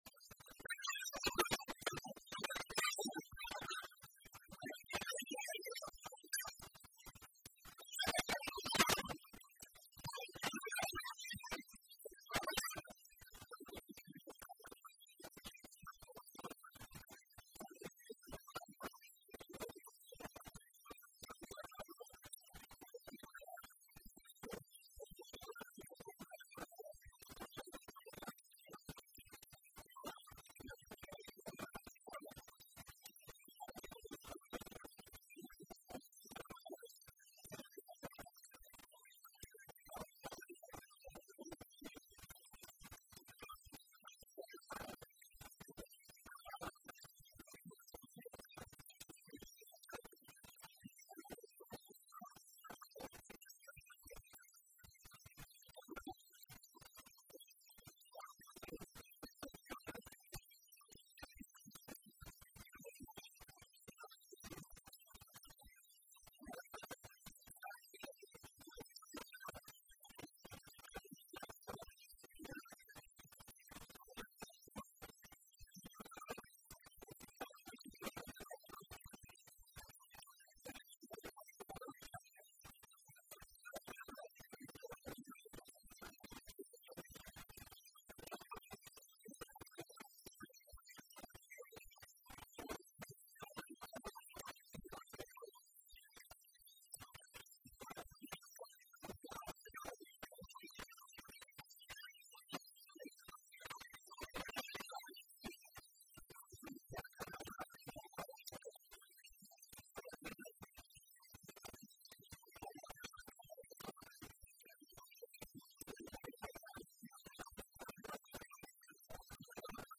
waraysi-kooban.mp3